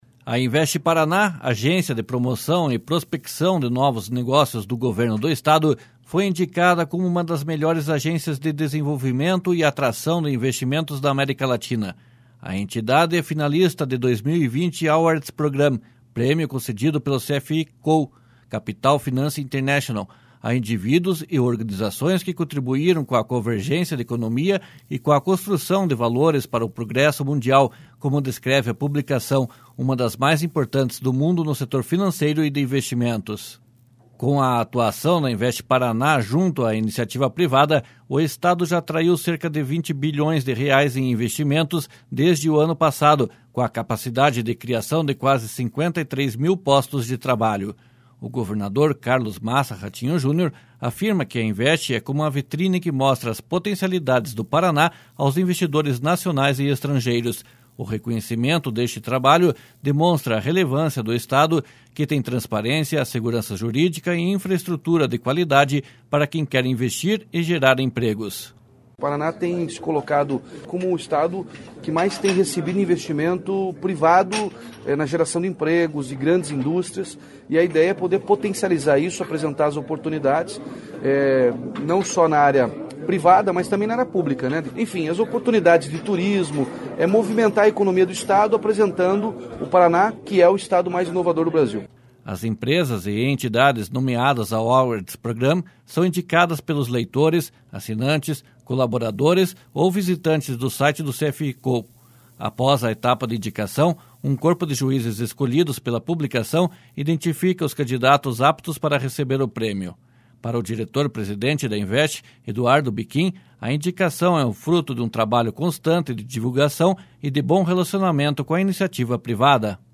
O reconhecimento deste trabalho demonstra a relevância do Estado, que tem transparência, segurança jurídica e infraestrutura de qualidade para quem quer investir e gerar empregos.//SONORA RATINHO JUNIOR//